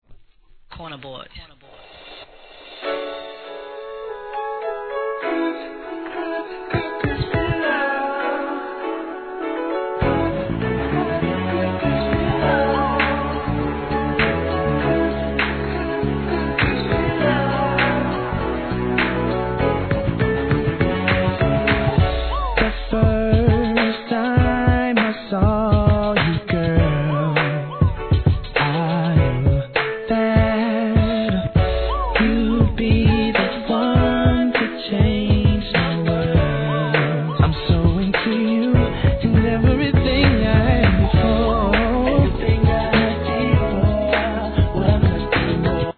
1. HIP HOP/R&B
スロウでムーディー、甘〜いセレクトで今回もバカ売れ確実！！